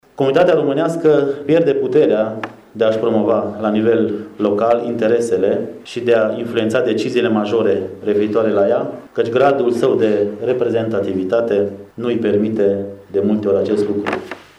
Prefectul judeţului Harghita, Andrei Jean-Adrian, a declarat azi, în cadrul lucrărilor Universităţii de Vară de la Izvorul Mureşului, că numărul românilor din judeţul Harghita se va reduce simţitor în următorii ani.
Prefectul de Harghita a spus că românii pierd puterea de a-şi promova la nivel local interesele datorită gradului de reprezentativitate şi a identificat şi câteva greşeli făcute de comunitatea locală românească în abordarea problemelor la nivel local: